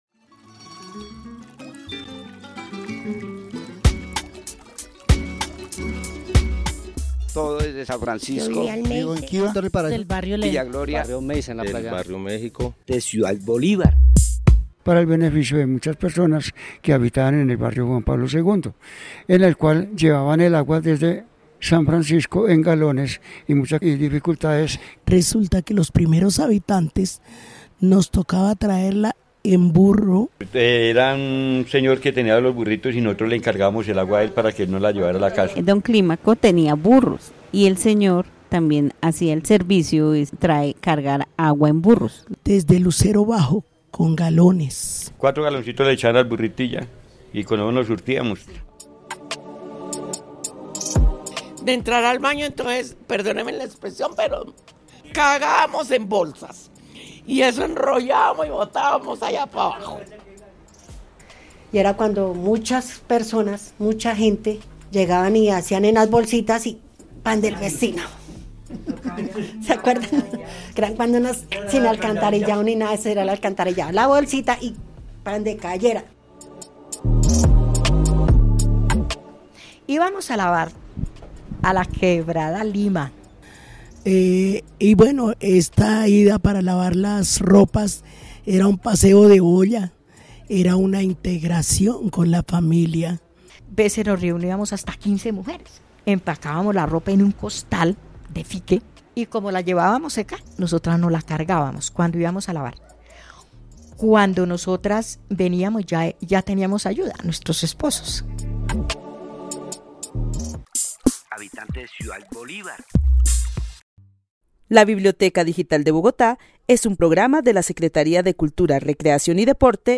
Testimonios sobre acceso a agua potable por medio de estrategias y organización comunitaria. La importancia de las lavanderas en la quebrada limas y demás actividades cotidianas para acceder a este recurso.